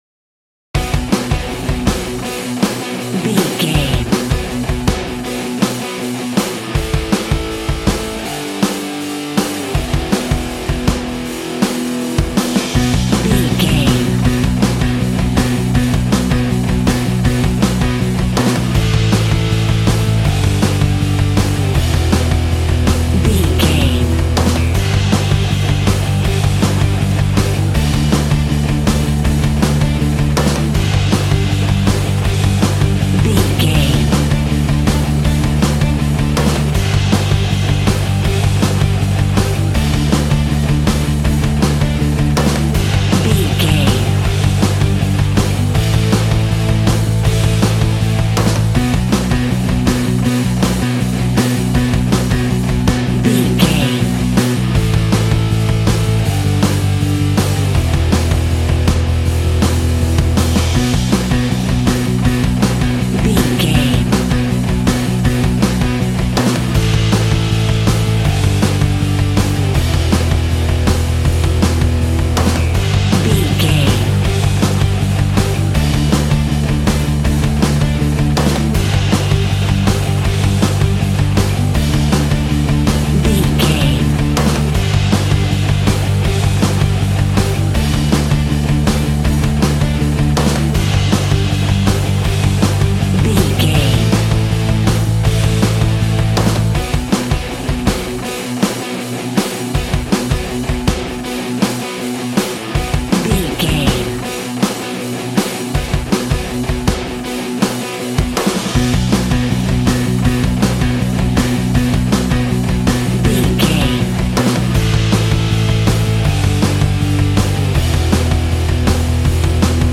Ionian/Major
D
angry
powerful
electric guitar
drums
bass guitar